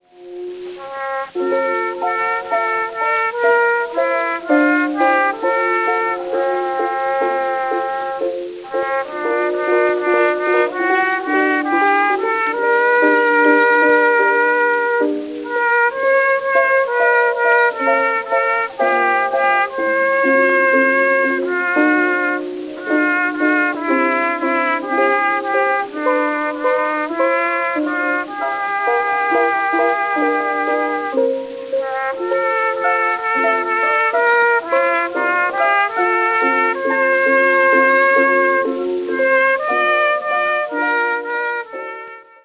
CORNET
original double-sided recordings made 1900-1922